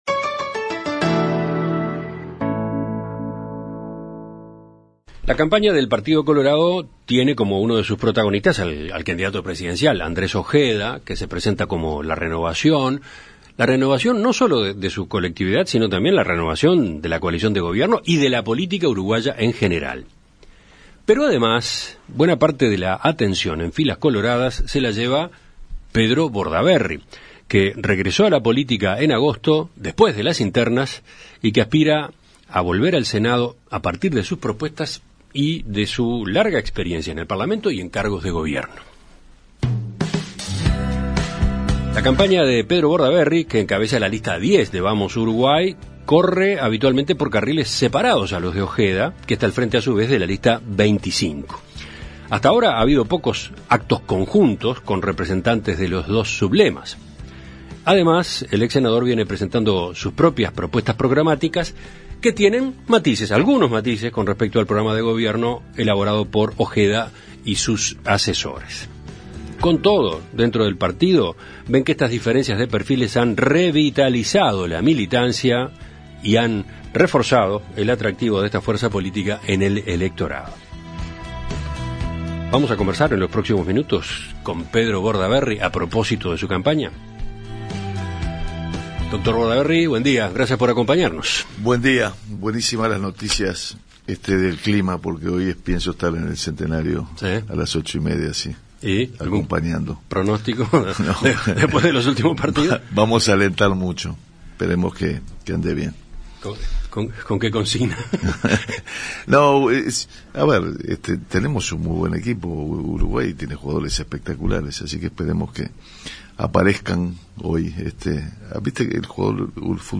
En Perspectiva Zona 1 – Entrevista Central: Pedro Bordaberry - Océano